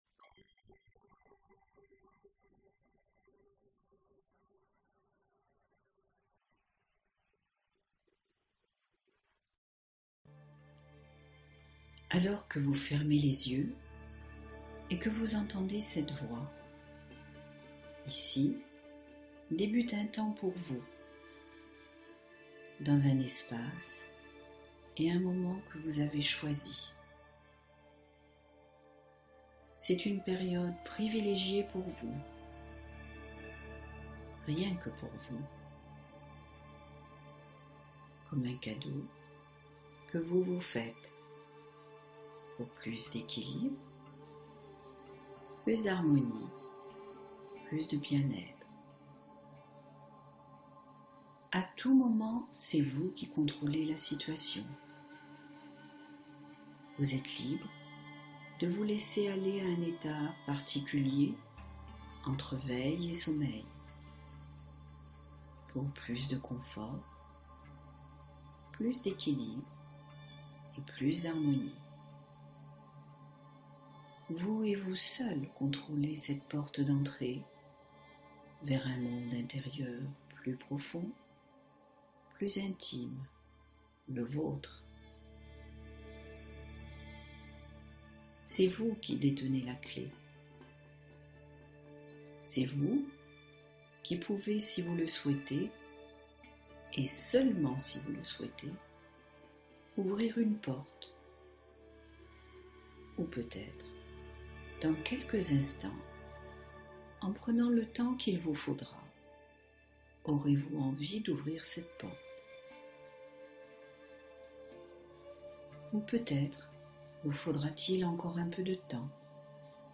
Installez la confiance en vous comme un logiciel : relaxation guidée programmante